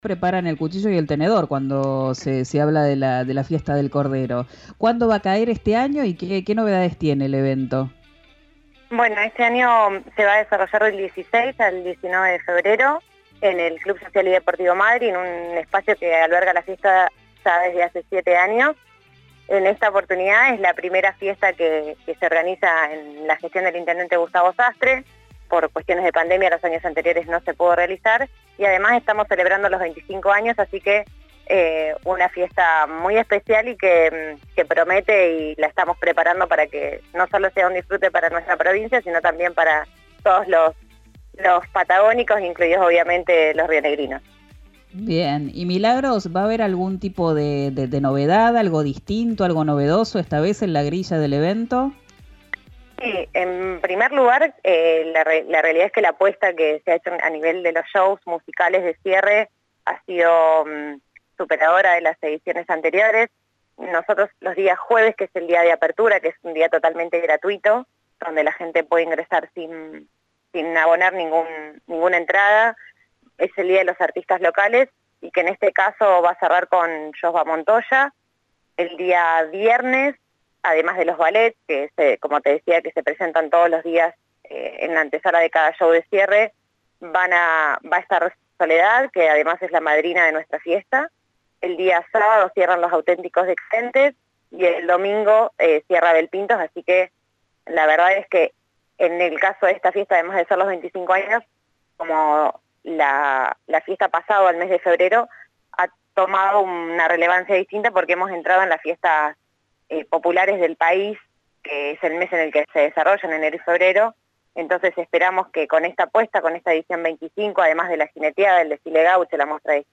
habló con "Quien dijo verano" por RÍO NEGRO RADIO para invitar a los turistas.